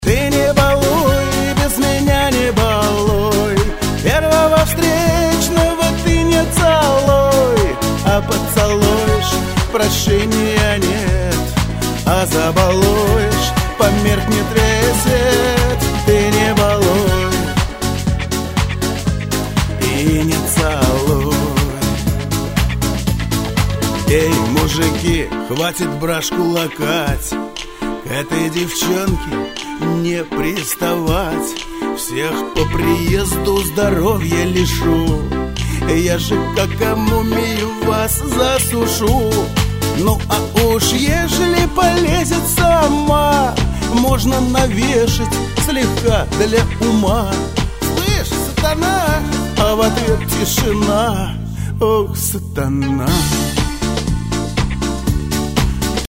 Категория: Шансон | Дата: 10.12.2012|